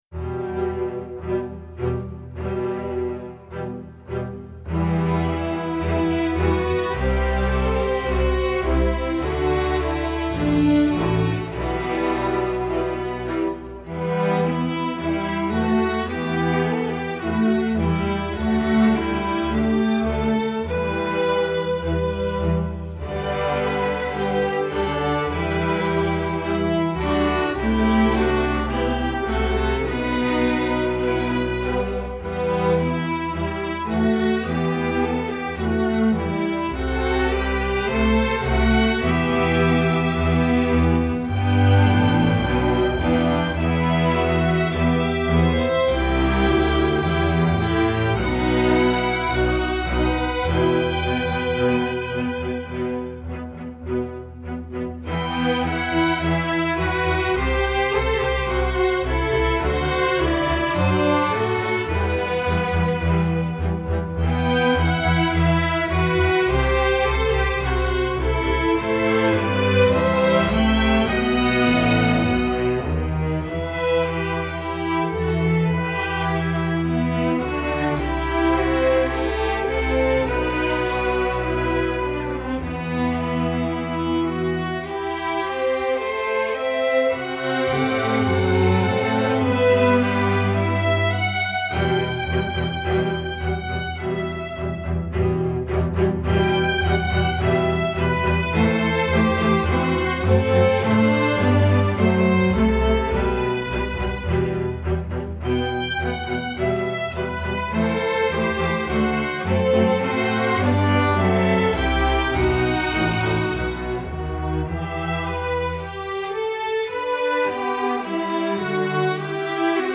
instructional, american